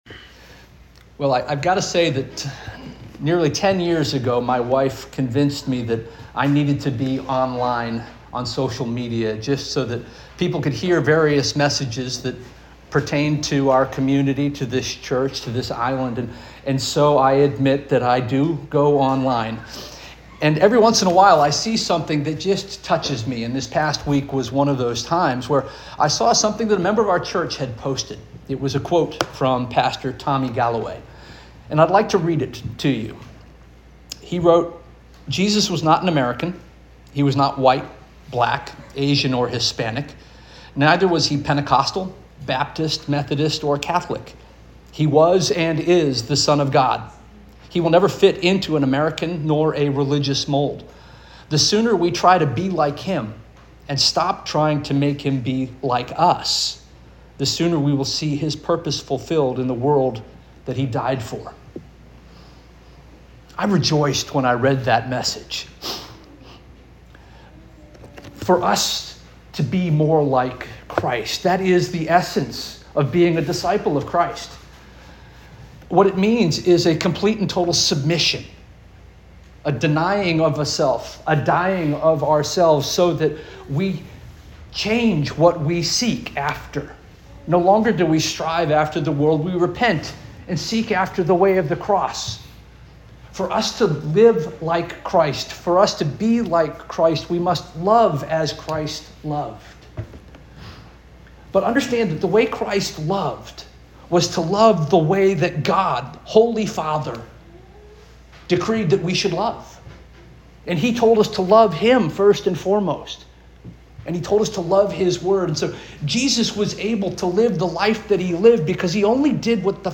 June 8 2025 Sermon